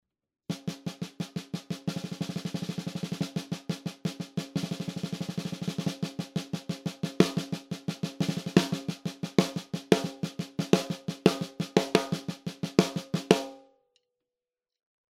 $60 Snare
Got a cheap noname steel snare 6,5"x14".